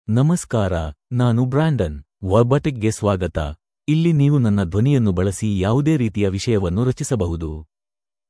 MaleKannada (India)
Brandon is a male AI voice for Kannada (India).
Voice sample
Male
Brandon delivers clear pronunciation with authentic India Kannada intonation, making your content sound professionally produced.